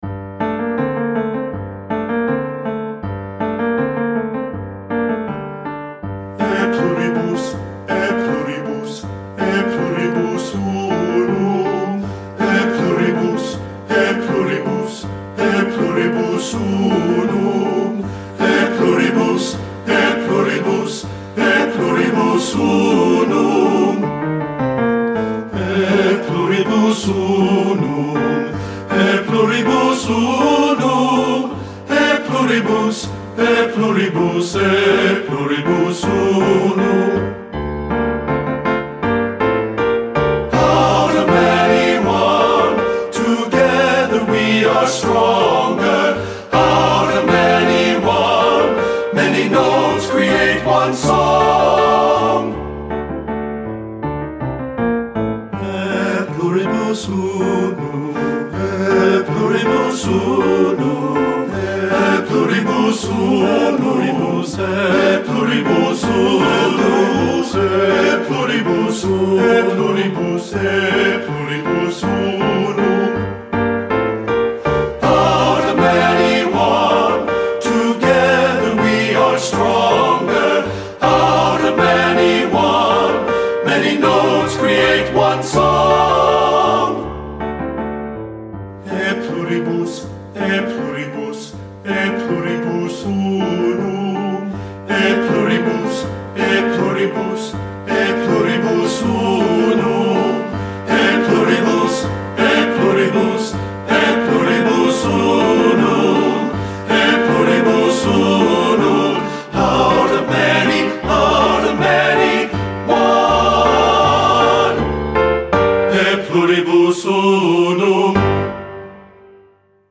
Voicing: TTB and Piano